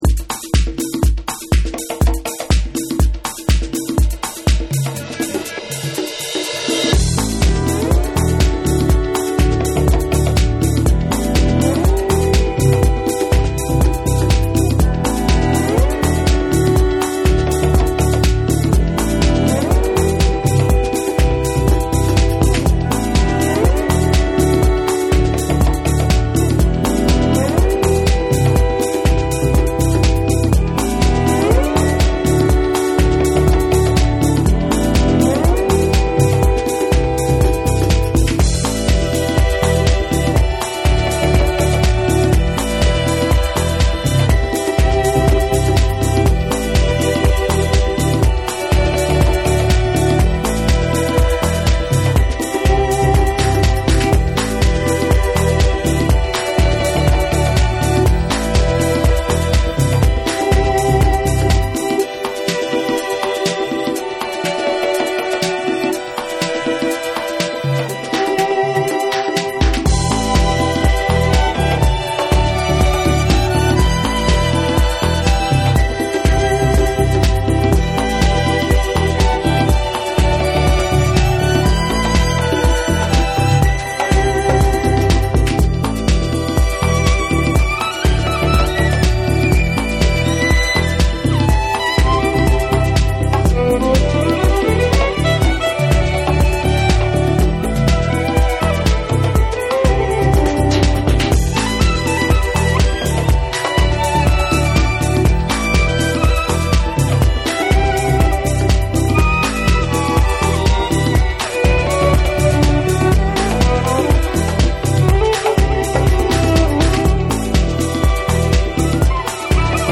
JAPANESE / TECHNO & HOUSE